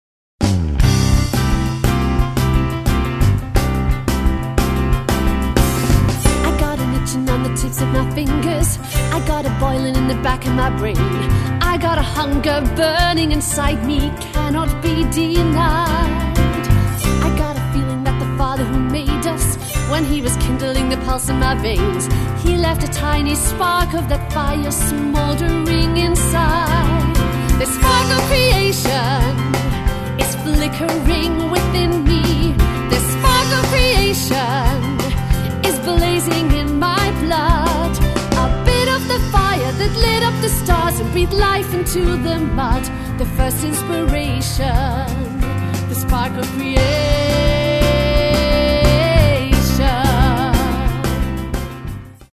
Soloist